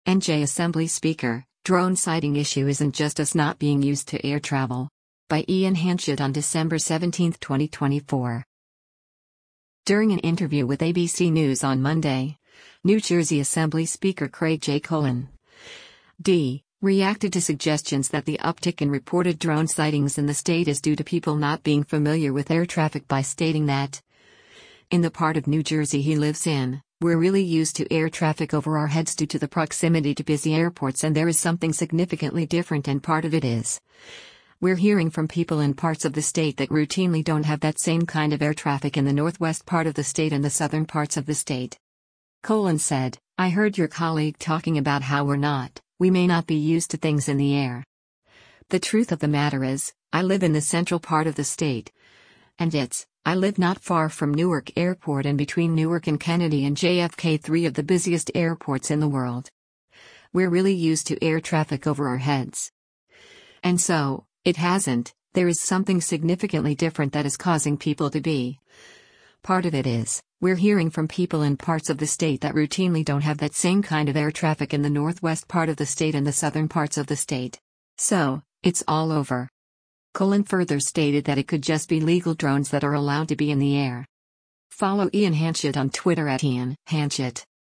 During an interview with ABC News on Monday, New Jersey Assembly Speaker Craig J. Coughlin (D) reacted to suggestions that the uptick in reported drone sightings in the state is due to people not being familiar with air traffic by stating that, in the part of New Jersey he lives in, “We’re really used to air traffic over our heads” due to the proximity to busy airports and “there is something significantly different” and “part of it is, we’re hearing from people in parts of the state that routinely don’t have that same kind of air traffic in the northwest part of the state and the southern parts of the state.”